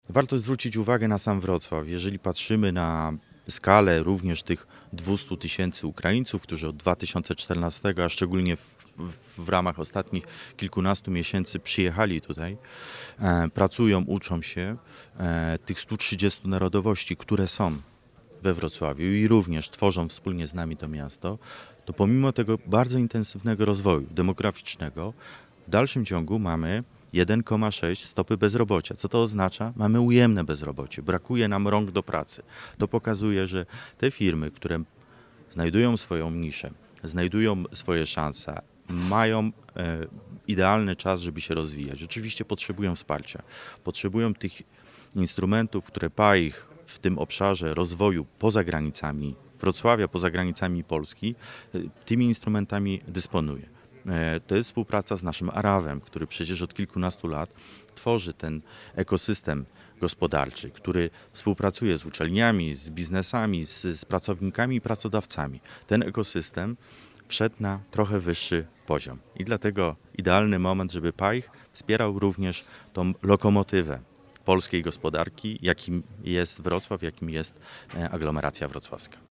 Jakub Mazur, wiceprezydent Wrocławia odniósł się do aktualnego rynku pracy i stopy bezrobocia.